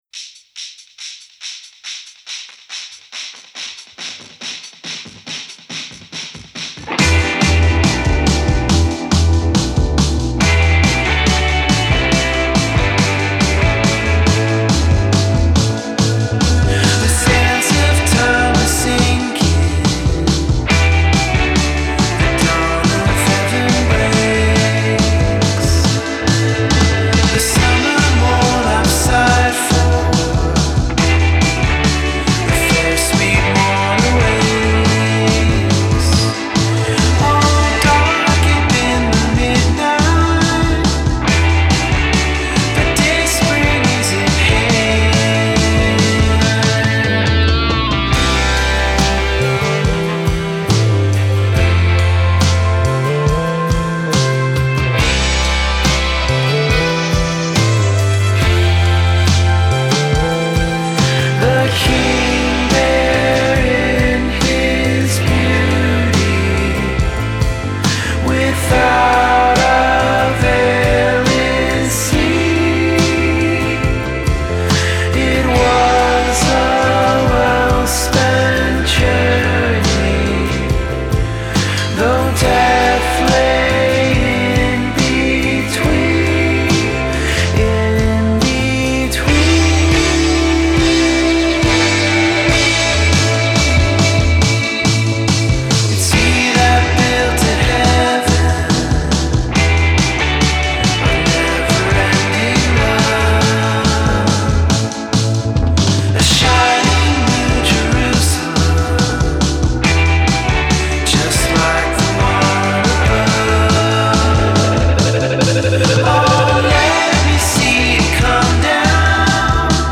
Genre: Alternative, Indie Rock